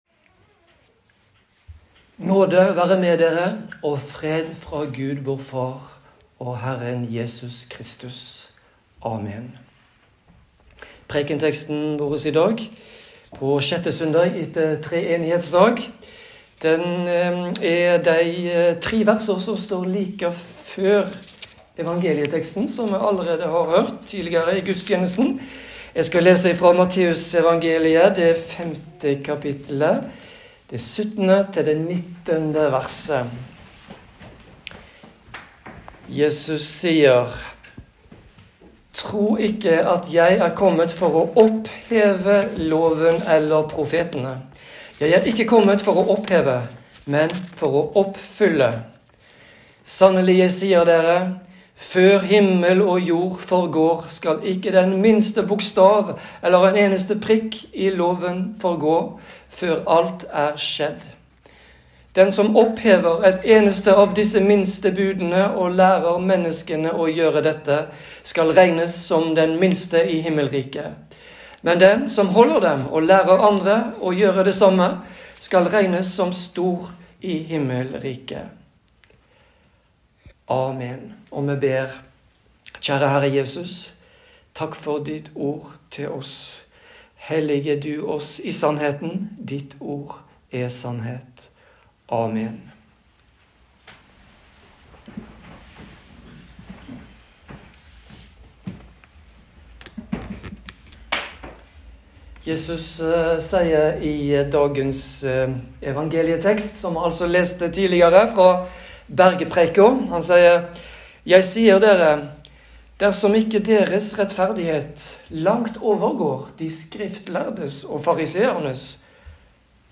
Preken på 6. søndag etter treenighetsdag